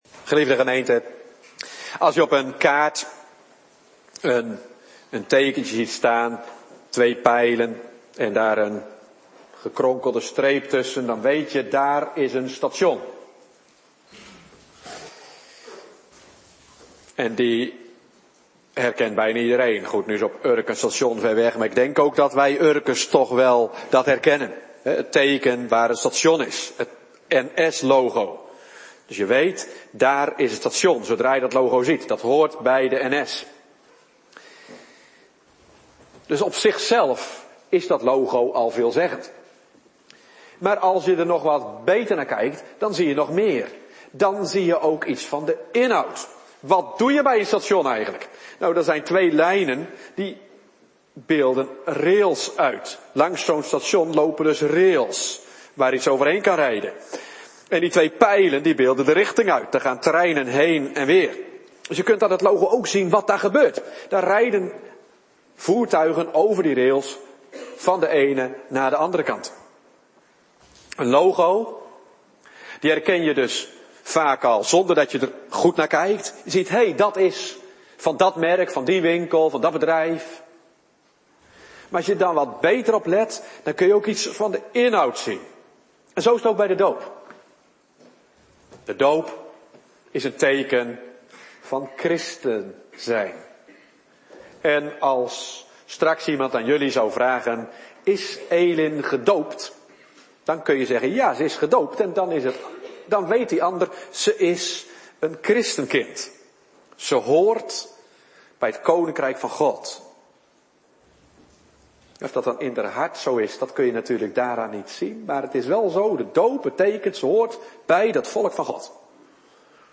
Soort Dienst: Bediening Heilige Doop Tekst